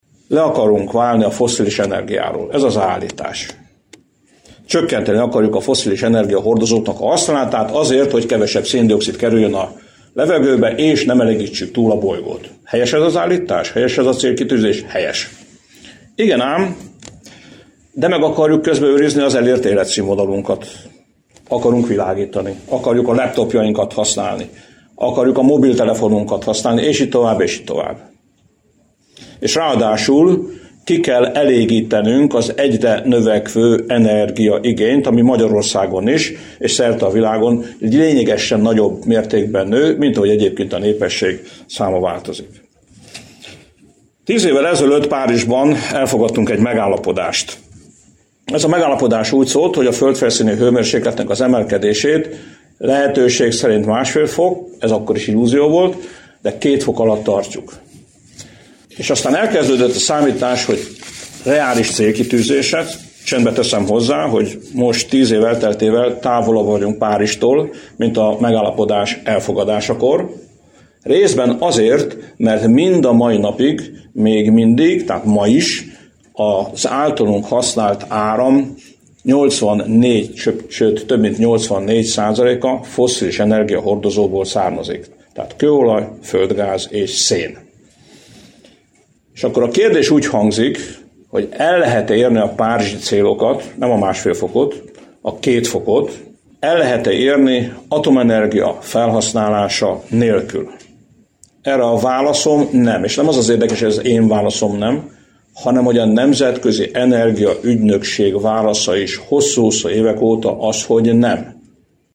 A fosszilis energiahordozókról való leválás nem fog sikerülni atomenergia felhasználása nélkül – mondta Áder János volt köztársasági elnök pénteken Pakson, a paksi atomerőmű alapkőletételének 50. évfordulója alkalmából tartott sajtótájékoztatón. Az erőmű látogatóközpontjában tartott rendezvényen a Kék Bolygó Klímavédelmi Alapítvány kuratóriumi elnöke és a Fenntartható Atomenergiáért Tanácsadói Testület tagja hozzátette: a Nemzetközi Energia Ügynökség szerint az atomenergiára a párizsi klímacélok eléréséhez is szükség van.